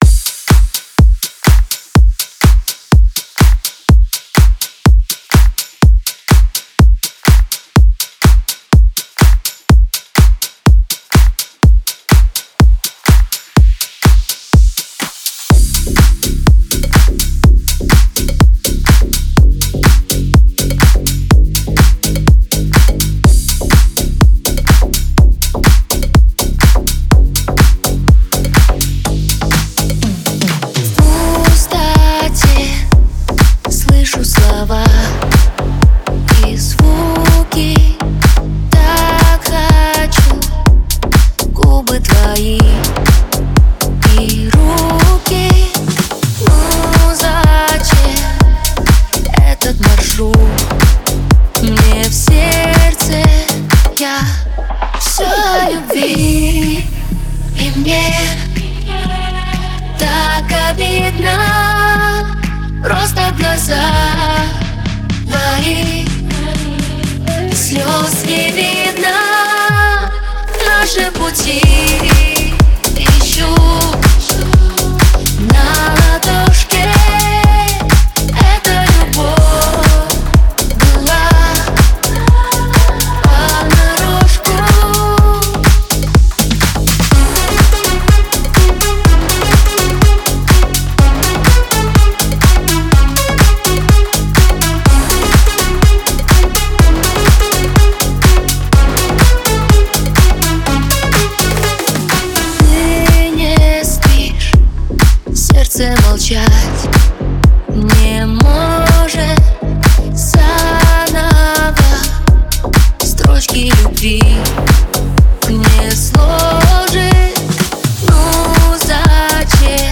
Категория: Танцевальная музыка